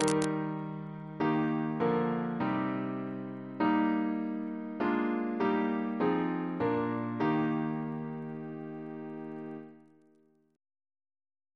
Single chant in E♭ Composer: William Russell (1777-1813) Reference psalters: ACB: 226; OCB: 327